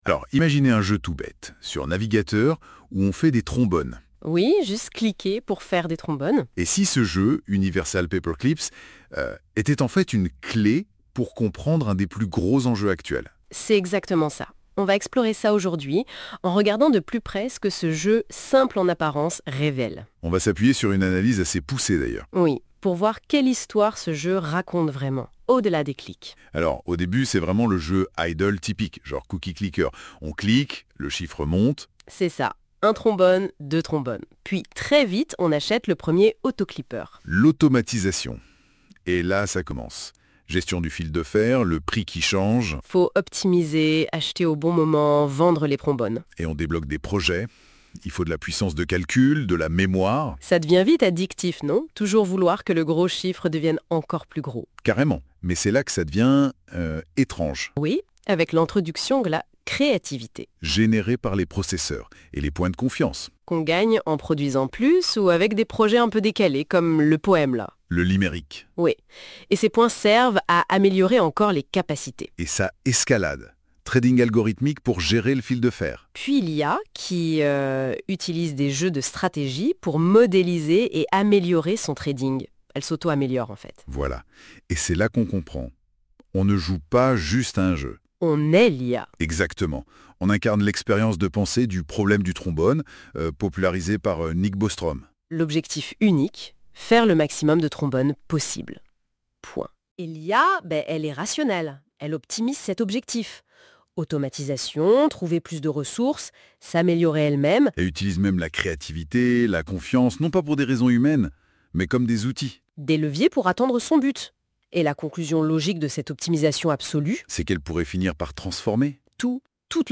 [!Success]Ecoutez la conversation !